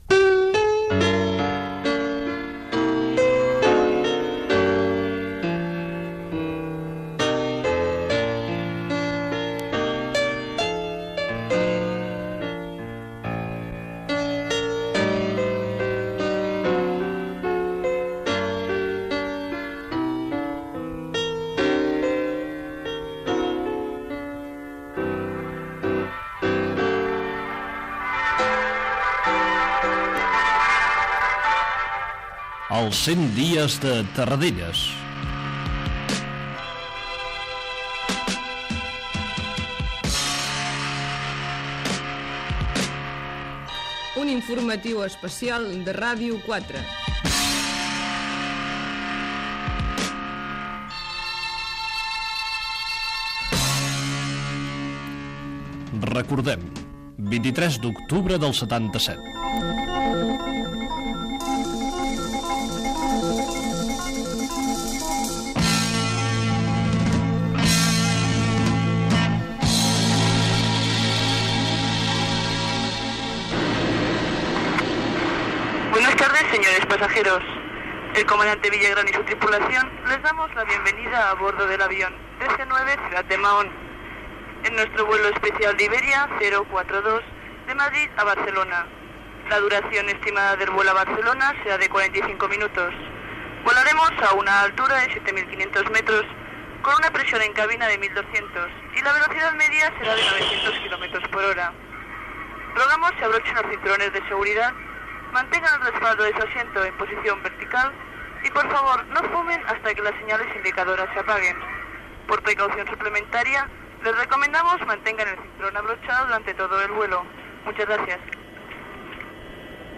Especial informatiu amb motiu dels cents dies del retorn del president de la Generalitat Josep Tarradellas a Catalunya